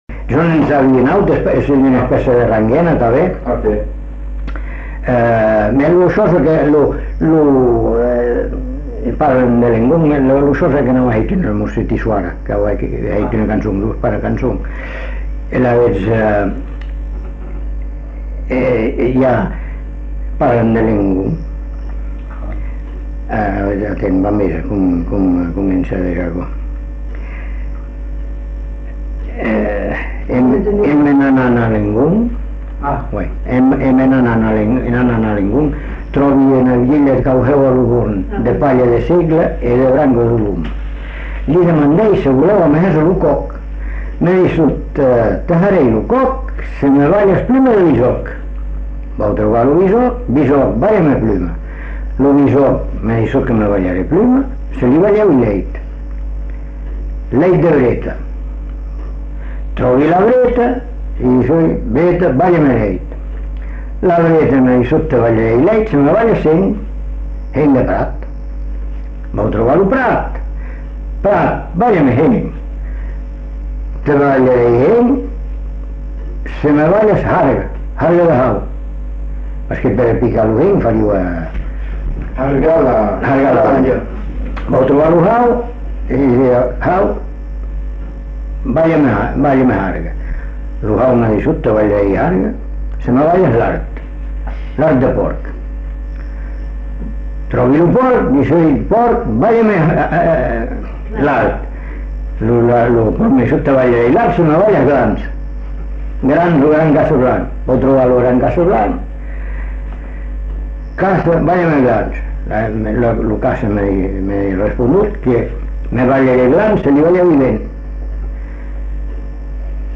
Aire culturelle : Bazadais
Lieu : Bazas
Genre : conte-légende-récit
Effectif : 1
Type de voix : voix d'homme
Production du son : récité